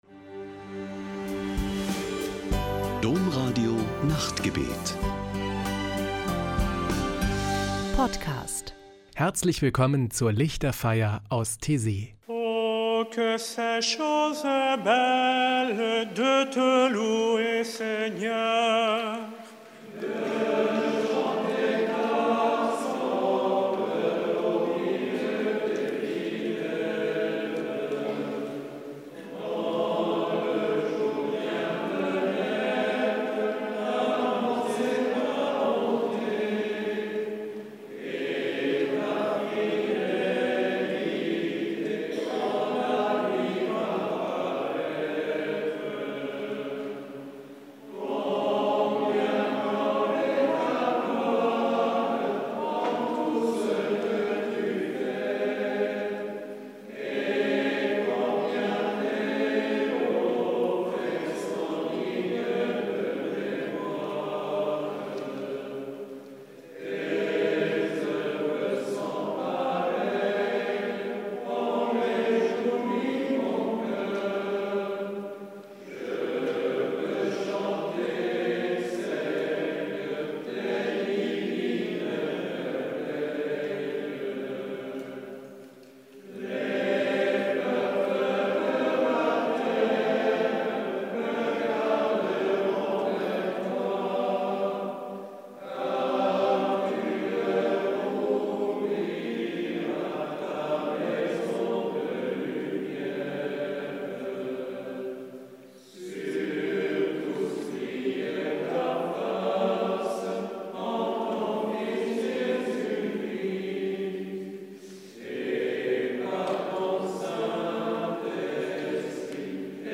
Ein Höhepunkt jede Woche ist am Samstagabend die Lichterfeier mit meditativen Gesängen und Gebeten.